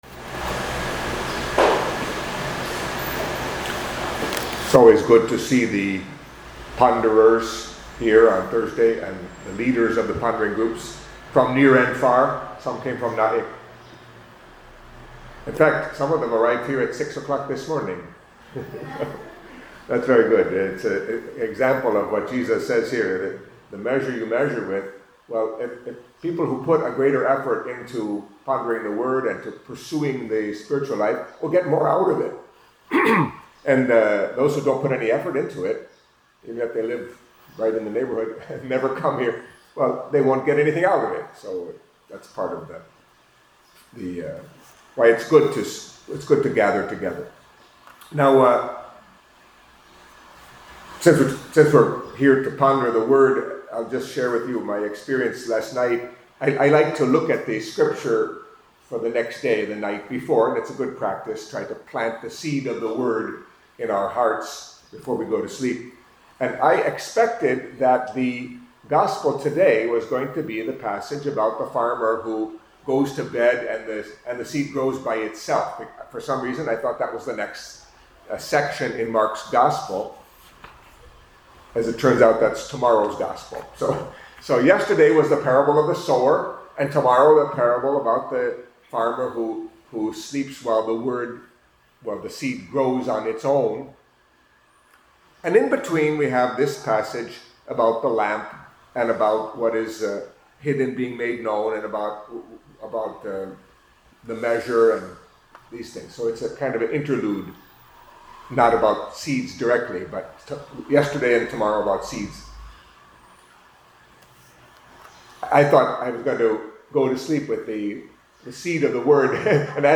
Catholic Mass homily for Thursday of the Third Week in Ordinary Time